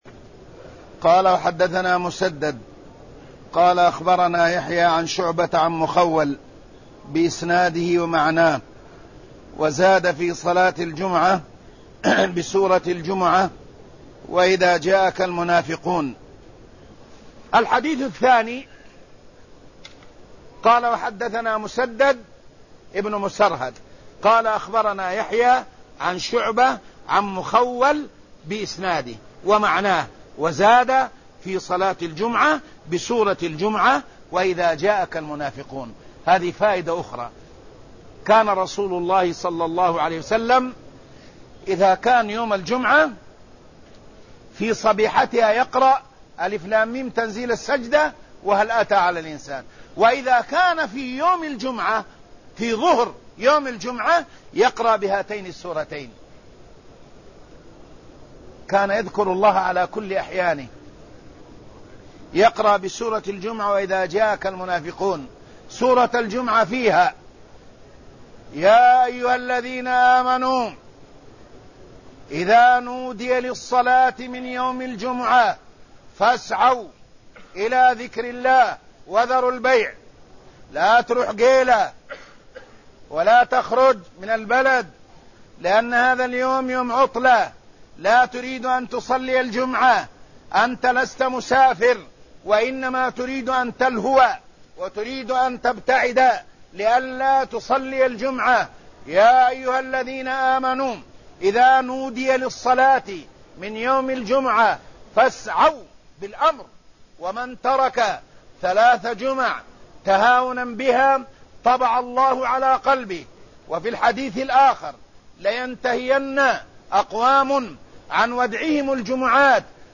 صلاة الجمعة بسورة الجمعة وإذا جاءك المنافقون (904)
المكان: المسجد النبوي الشيخ: فضيلة الشيخ عمر بن حسن فلاته فضيلة الشيخ عمر بن حسن فلاته صلاة الجمعة بسورة الجمعة وإذا جاءك المنافقون (904)